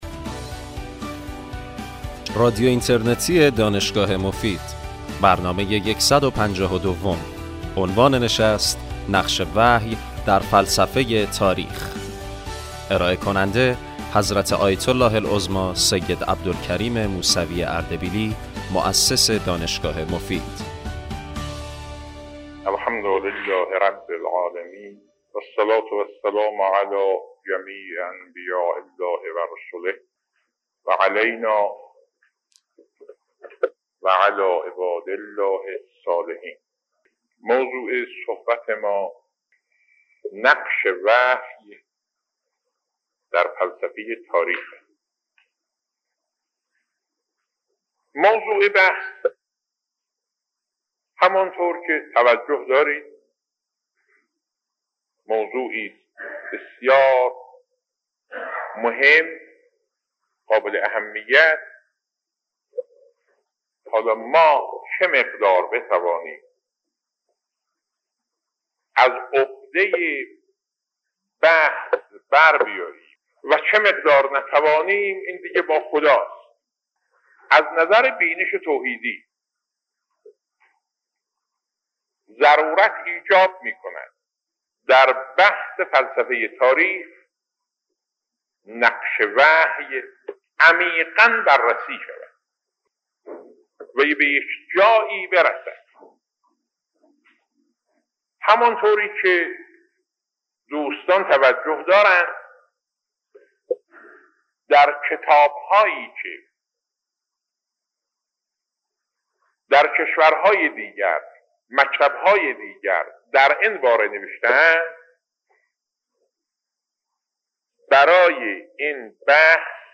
آیت الله العظمی موسوی اردبیلی در این سخنرانی که در اواسط دهه 60 ایراد نموده اند، به ضرورت بررسی نقش وحی در فلسفه تاریخ پرداخته و بیان می دارند که در این زمینه پژوهش شاخصی حتی در آثار مستشرقین و تاریخ پژوهان غربی وجود ندارد. ایشان با اشاره به تاریخچه در دسترس مناطق جغرافیایی بعثت پیامبران الهی، به خصلت های درون ذاتی دعوت انبیاء مانند اخلاق، نفی شرک، عدالت و .. اشاره نموده و قرآن را مهمترین ماخذ برای بررسی بینش حاکم بر دعوت معرفی می نمایند.